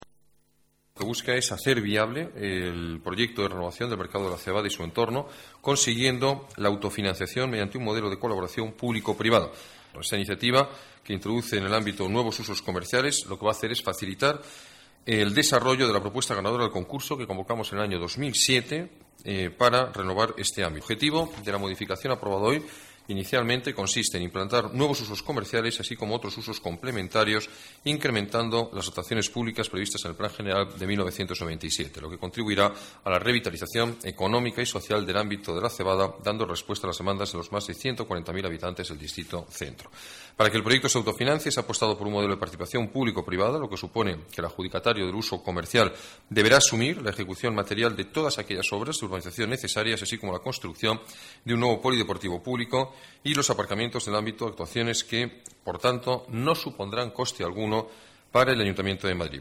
Nueva ventana:Declaraciones alcalde, Alberto Ruiz-Gallardón: remodelación Mercado de La Cebada